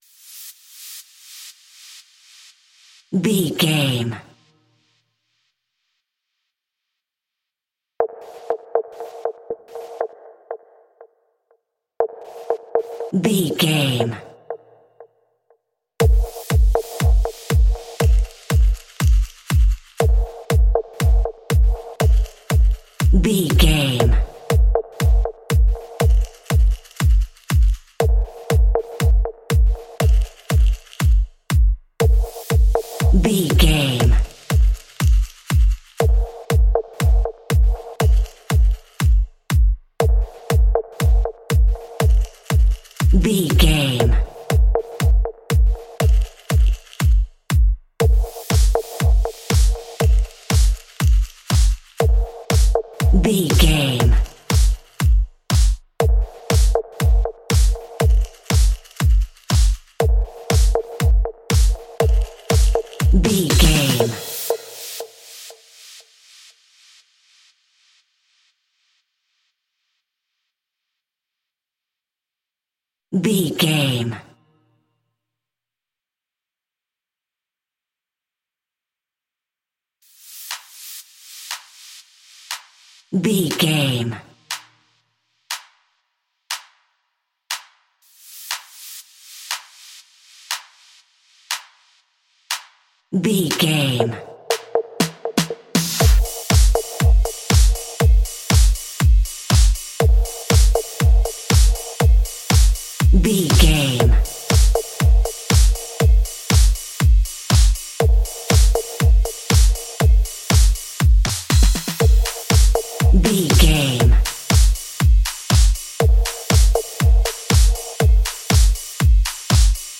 Aeolian/Minor
driving
bouncy
drum machine